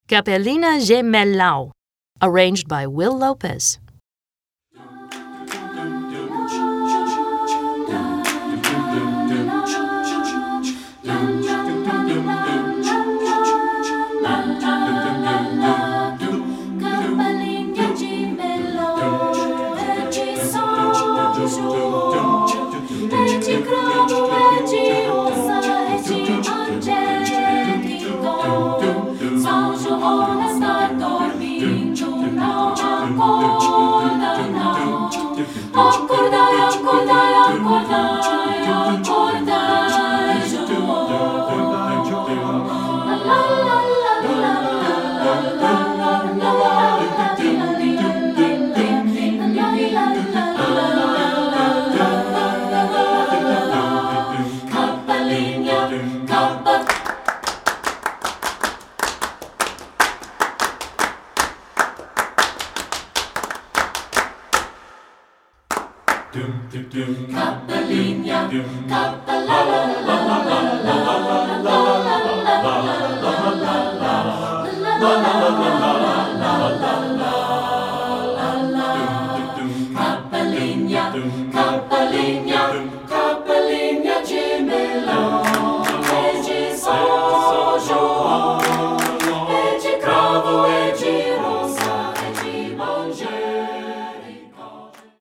Choral Multicultural
For SATB A Cappella and Percussive Movements.
Brazilian Folk Song
SATB A Cap